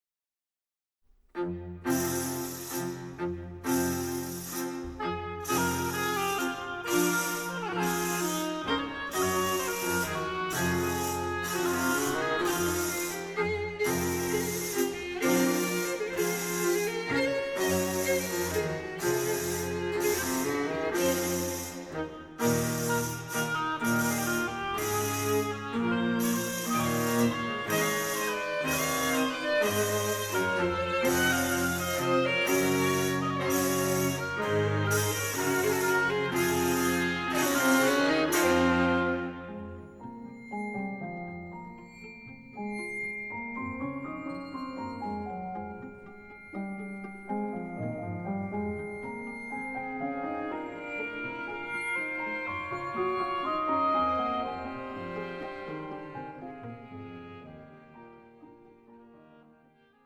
Dance Suite for Oboe, Strings, Piano and Percussion